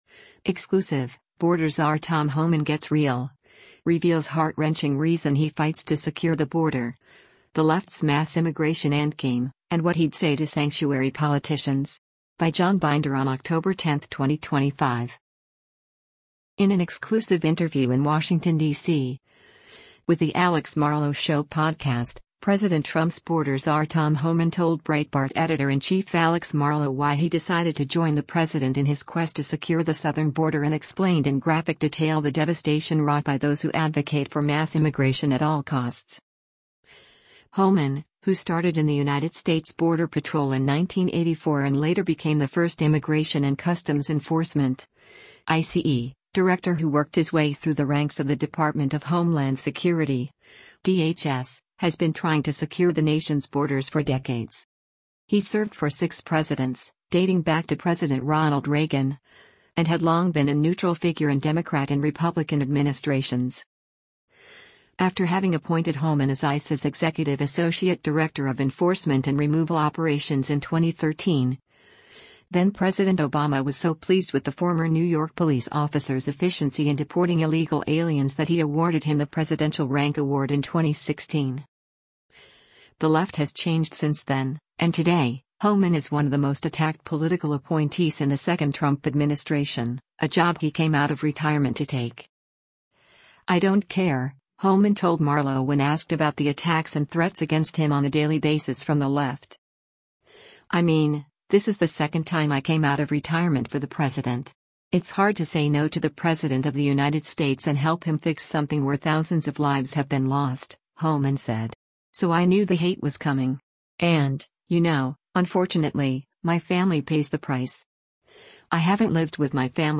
Border Czar Tom Homan sat down for an exclusive interview in Washington, DC, for "The Alex Marlow Show" podcast.
In an exclusive interview in Washington, DC, with The Alex Marlow Show podcast, President Trump’s Border Czar Tom Homan told Breitbart Editor-in-Chief Alex Marlow why he decided to join the president in his quest to secure the southern border and explained in graphic detail the devastation wrought by those who advocate for mass immigration at all costs.